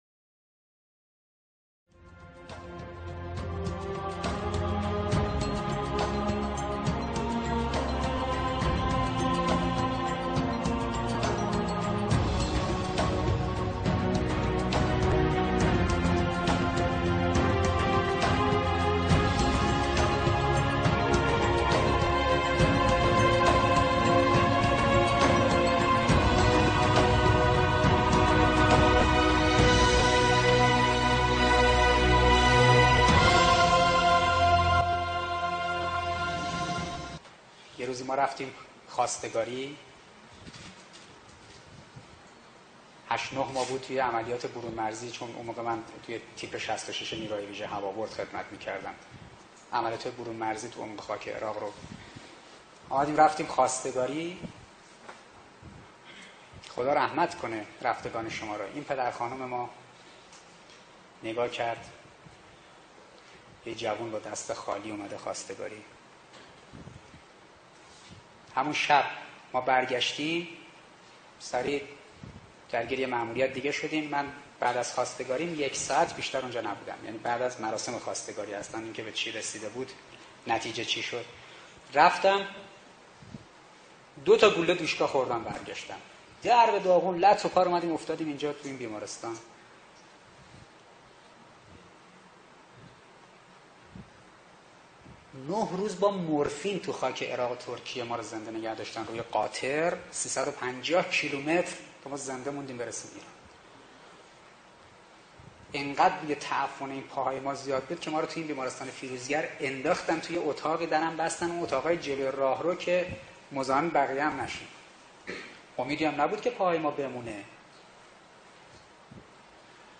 صدای مشاور